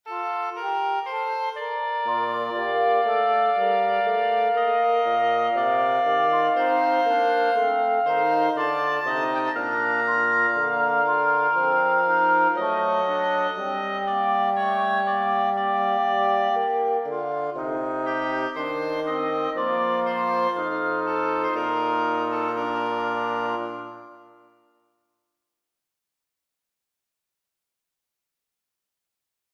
Short piece for wind quartet in Bb
I wrote this short piece to practice counterpoint, creating some rythmic variety between parts and using some rythmic imitation.